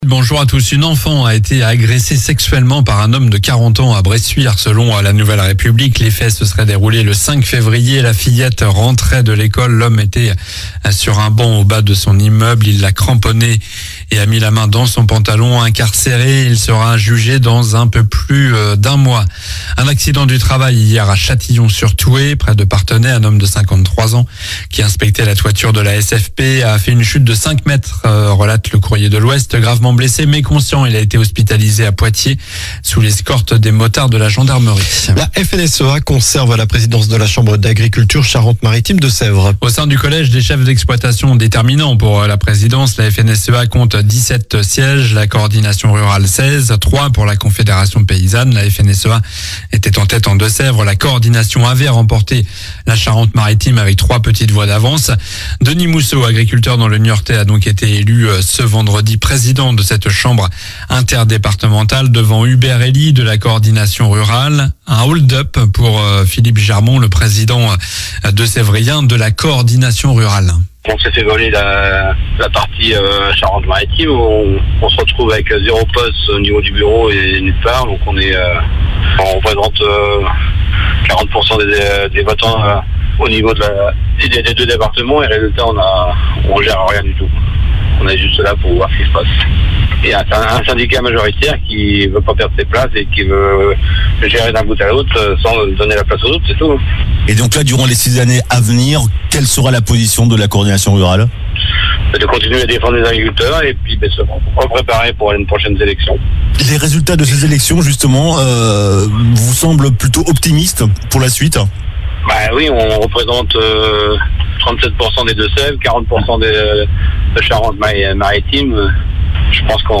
Journal du samedi 22 février (matin)